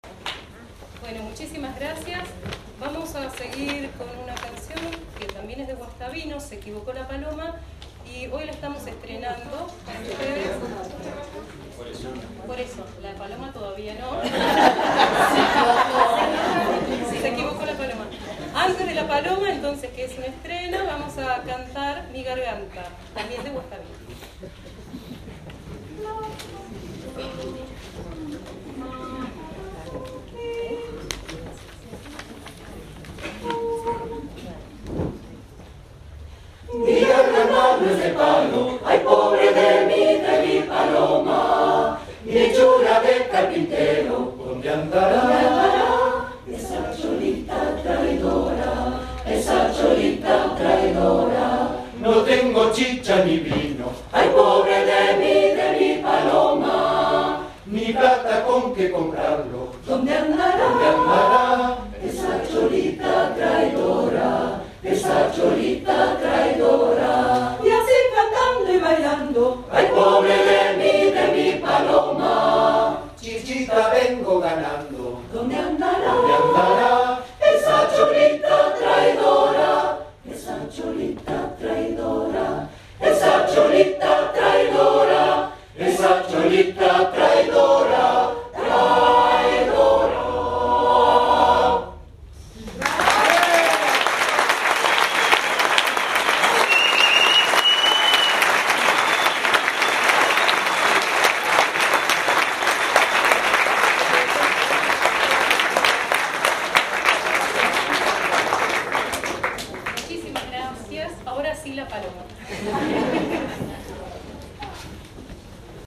Negro Spiritual